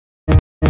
Entrevistas en SurFM, imagen de PodCast